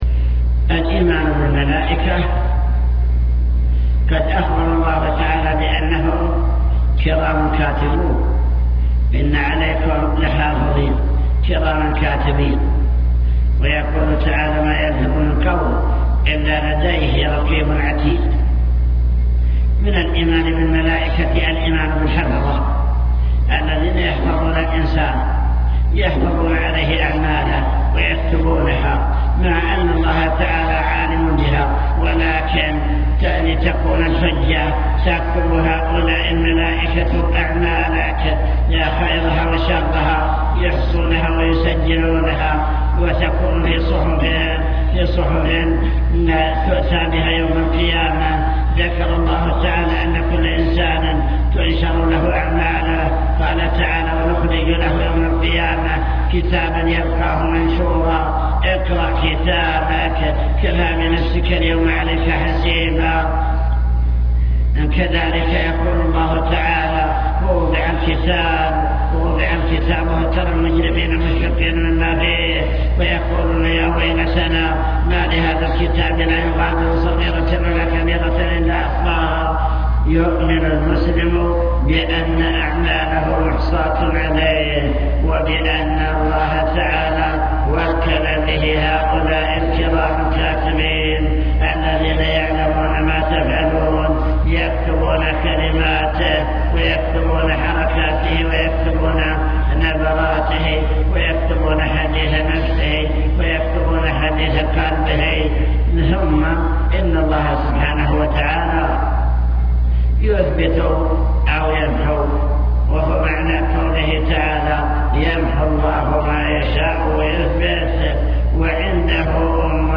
المكتبة الصوتية  تسجيلات - محاضرات ودروس  محاضرة الإيمان باليوم الآخر أمور غيبية يجب الإيمان بها